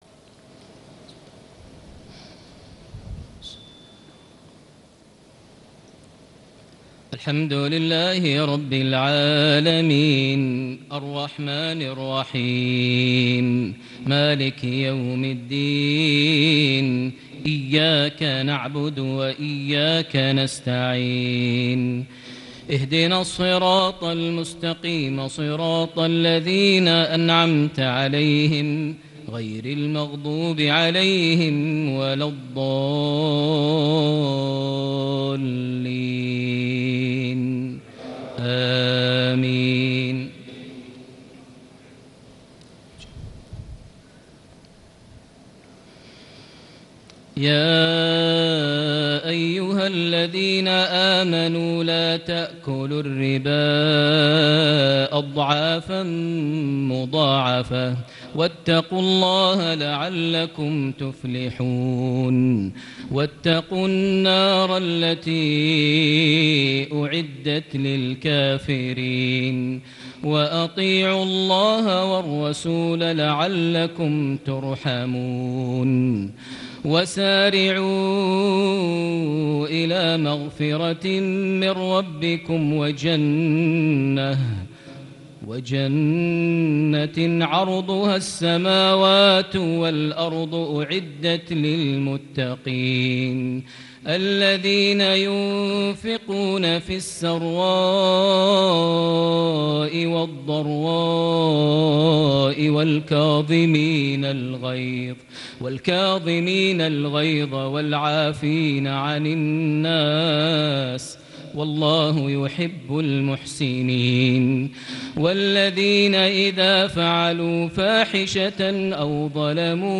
صلاة فجر ١٠ ذي الحجة ١٤٣٨هـ سورة آل عمران ١٣٠-١٤١ > 1438 هـ > الفروض - تلاوات ماهر المعيقلي